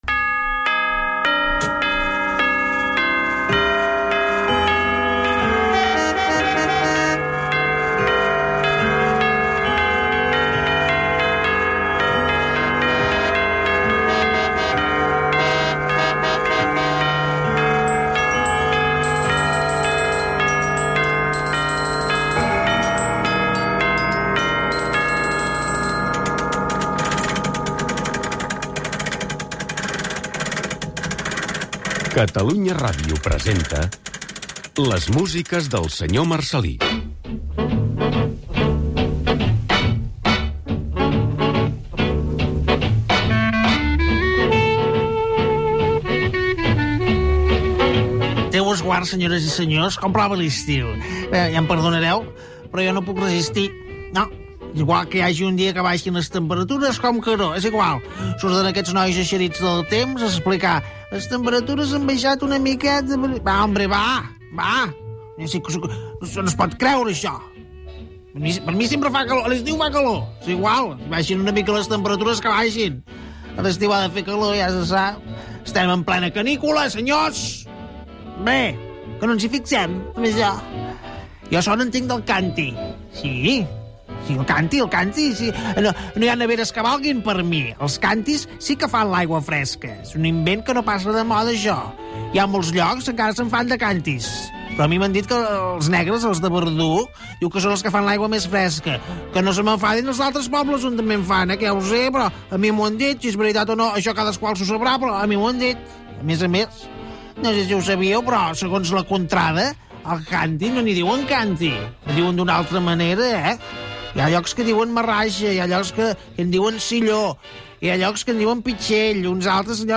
Careta del programa, comentari sobre la calor de l'estiu i els cantirs, tema musical
Musical